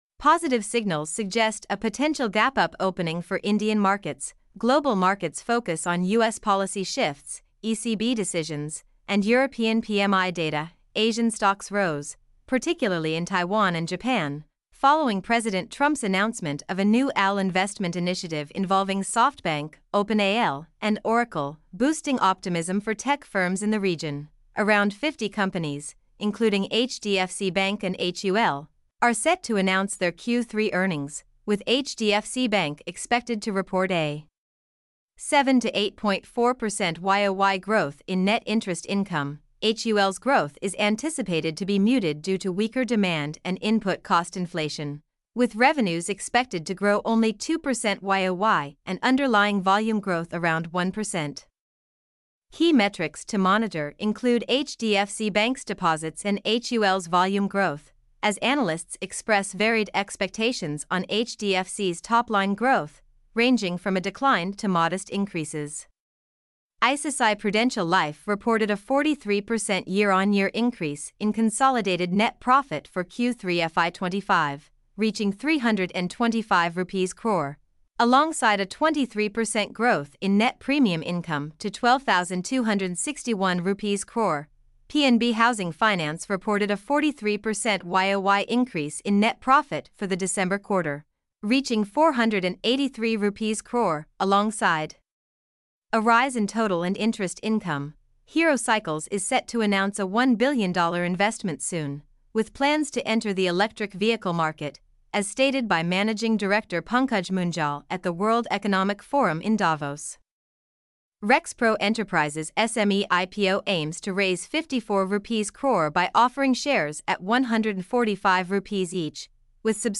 mp3-output-ttsfreedotcom-3.mp3